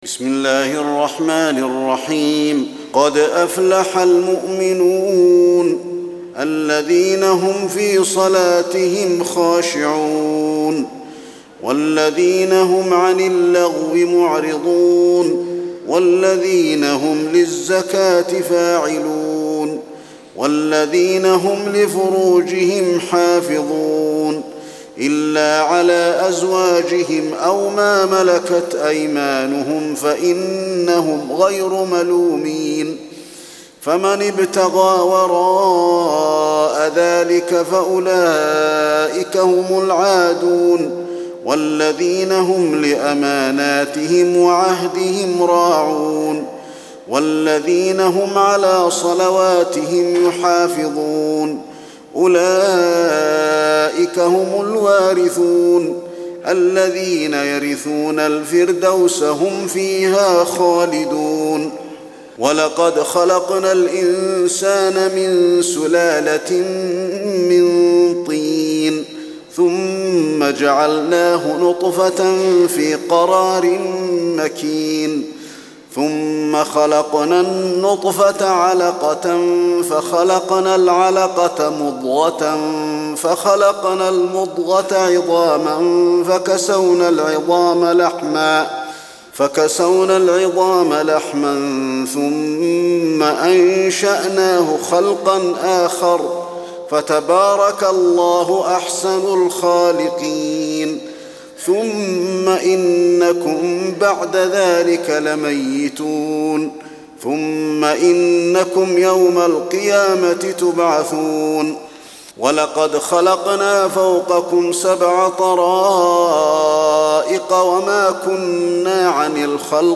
تراويح الليلة السابعة عشر رمضان 1423هـ سورة المؤمنون Taraweeh 17 st night Ramadan 1423H from Surah Al-Muminoon > تراويح الحرم النبوي عام 1423 🕌 > التراويح - تلاوات الحرمين